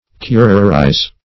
Curarize \Cu"ra*rize\ (-r?z)